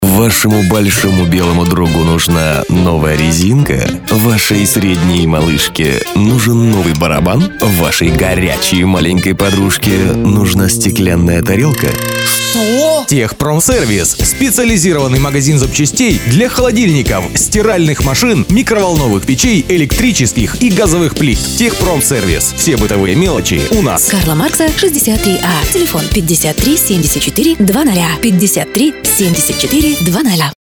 Радиоролик специализированного магазина (сценарий) Категория: Копирайтинг